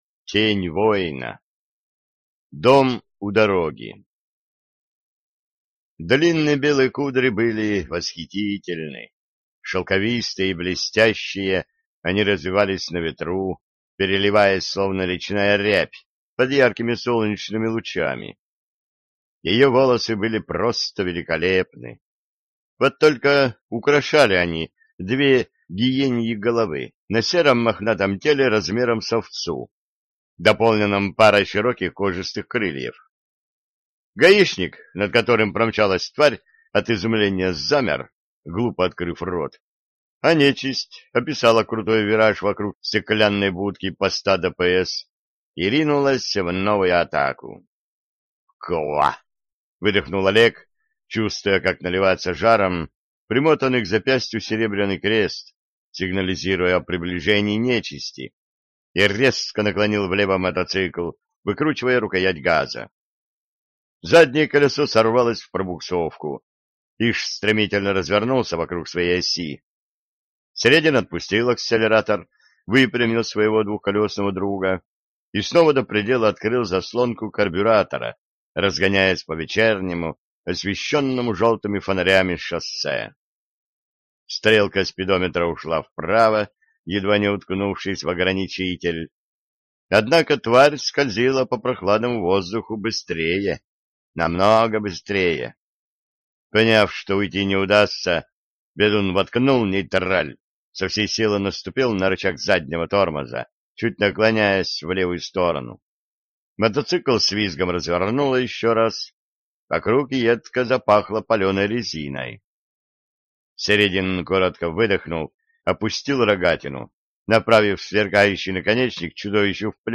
Аудиокнига Тень воина | Библиотека аудиокниг